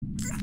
GiggleCry1.mp3